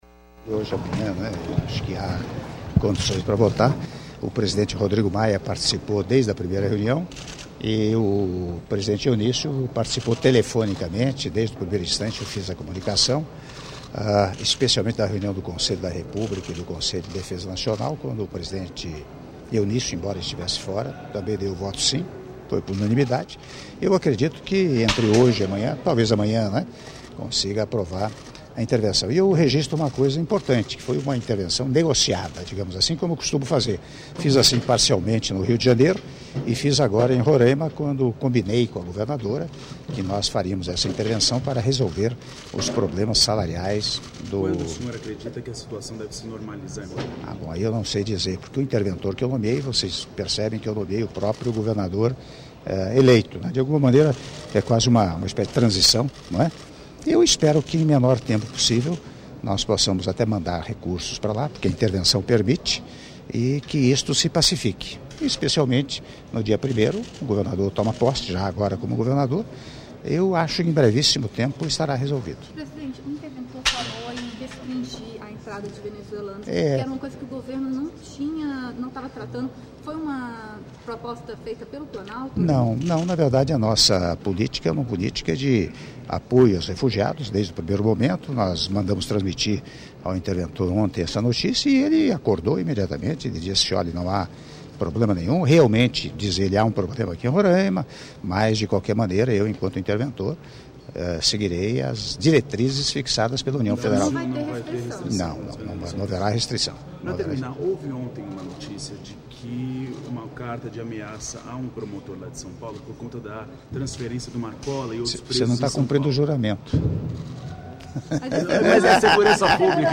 Entrevista coletiva concedida pelo Presidente da República, Michel Temer, após cerimônia de apresentação de cartas presidenciais- Palácio do Planalto (02 min 48s)
Palácio do Planalto, 10 de dezembro de 2018